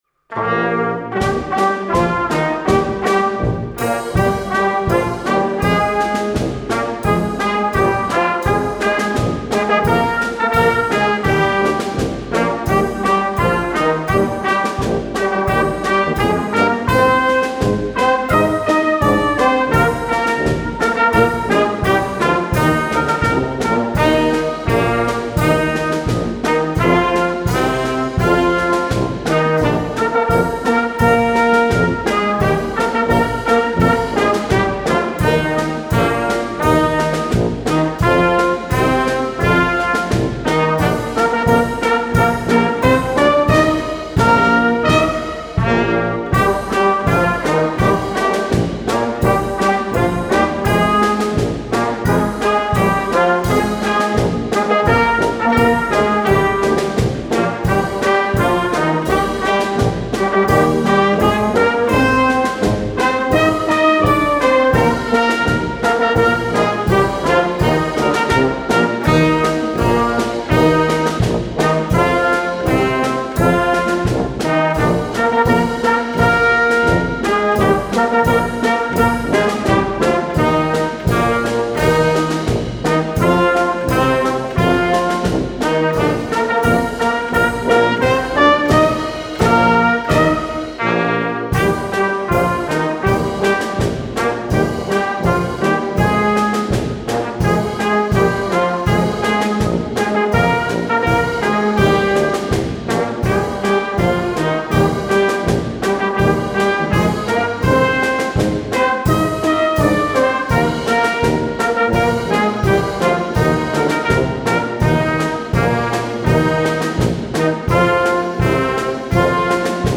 Ihr wollte das Hilzinger Narrenlied nicht ohne unsere Fasnetsmusik singen?
Der Pfiffikus und die Hansele singen für Euch das Hilzingen Narrenlied